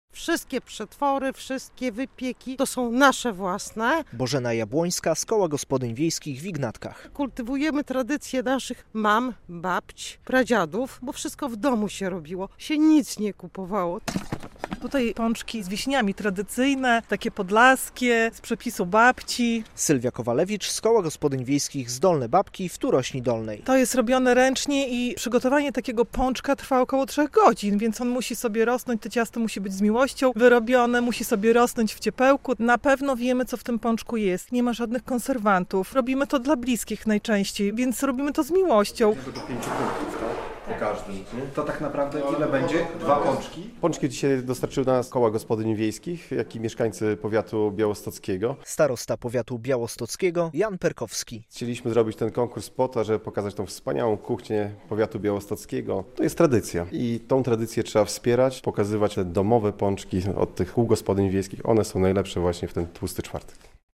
To jest tradycja i tę tradycję trzeba wspierać, pokazywać te domowe pączki - mówi starosta białostocki Jan Perkowski.